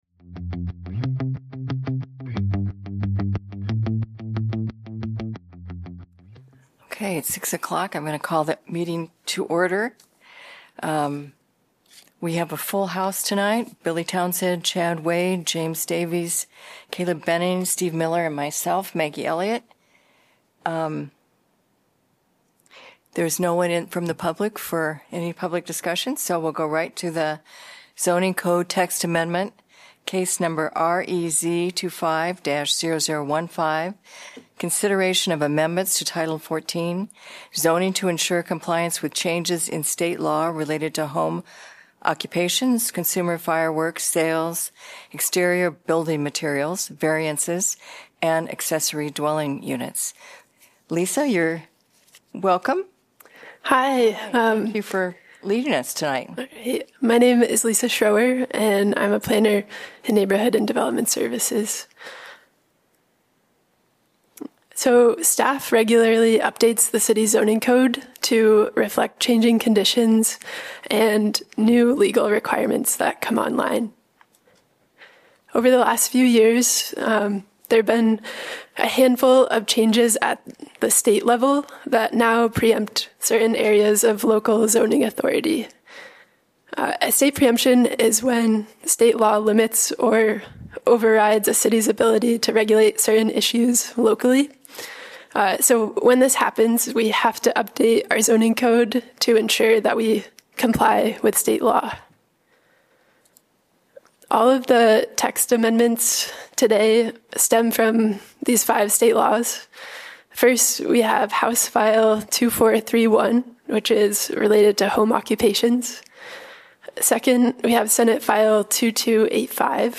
Regular semi-monthly meeting of the Planning and Zoning Commission.